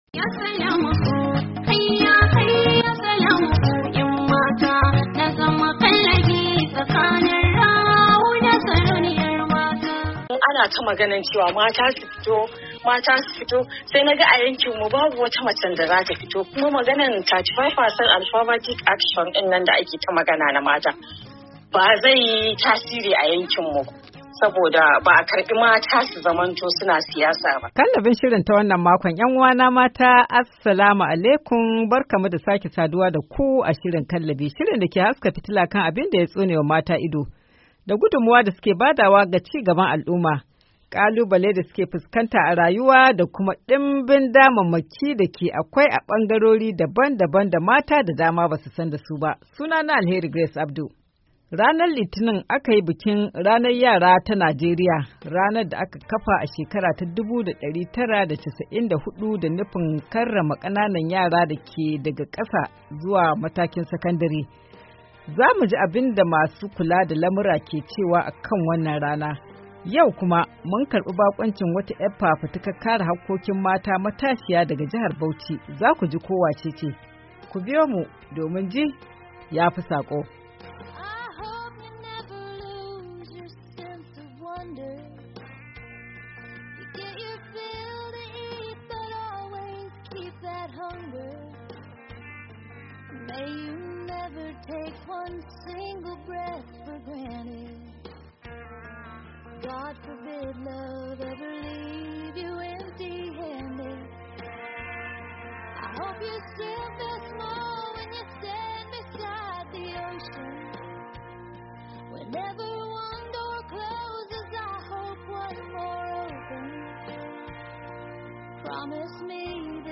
KALLABI: Tattaunawa Da Masu Kula Da Lamura Akan Tasirin Bikin Ranar Yara Ta Najeriya - Yuni 02, 2024